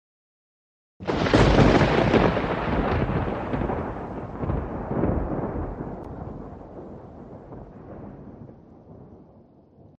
Free Nature sound effect: Hailstorm.
Hailstorm
Hailstorm is a free nature sound effect available for download in MP3 format.
530_hailstorm.mp3